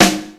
Index of /90_sSampleCDs/300 Drum Machines/Akai XR-10/Snaredrums
Snaredrum-02.wav